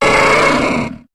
Cri de Tauros dans Pokémon HOME.